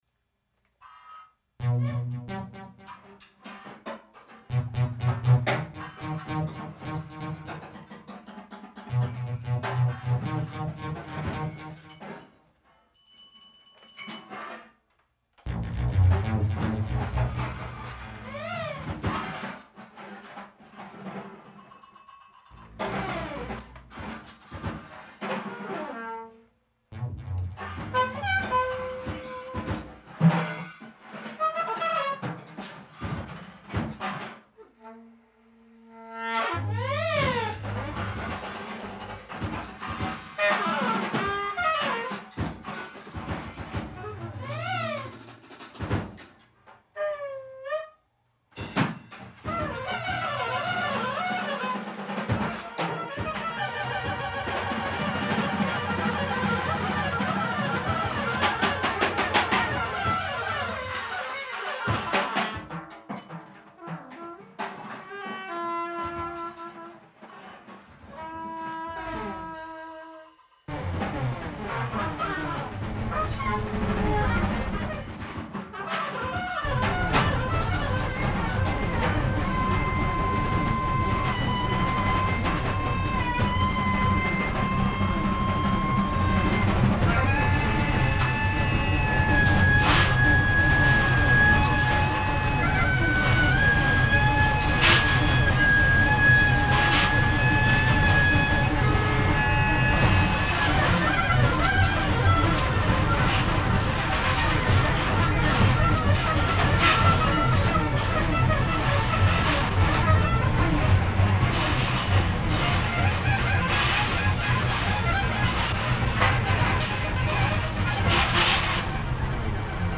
keyboard jam
saxophonist
drummer